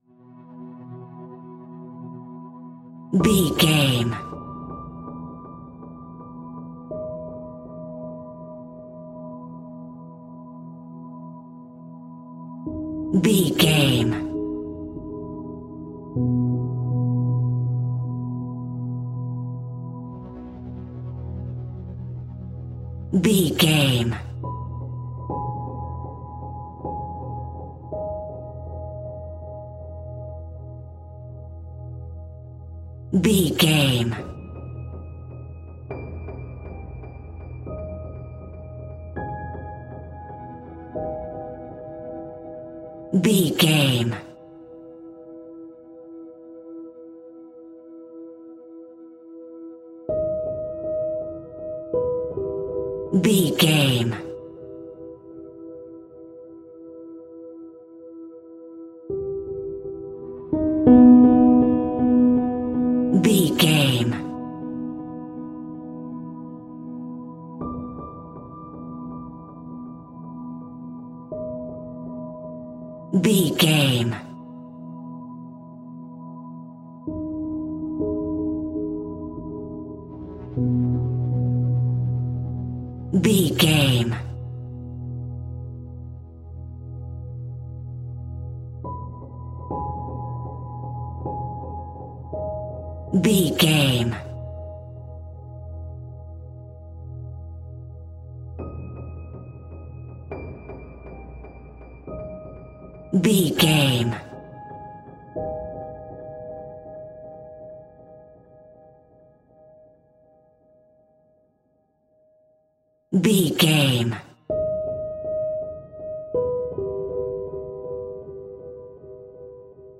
Twisted Dark Piano Drone Music.
Aeolian/Minor
ominous
haunting
eerie
sythesizer
horror music
Horror Pads